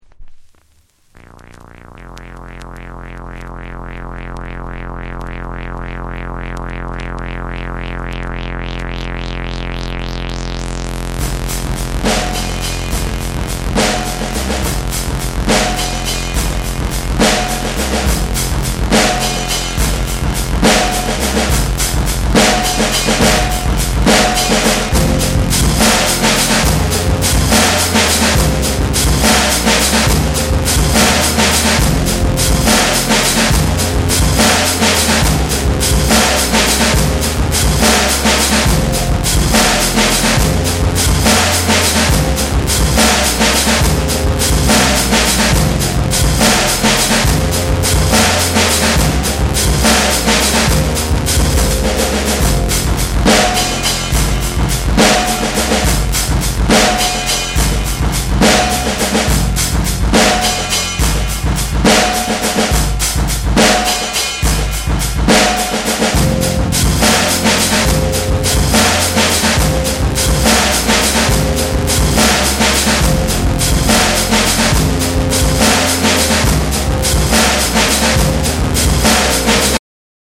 メランコリックで奥行きあるサウンド・プロダクションが光るブレイクビーツ！
BREAKBEATS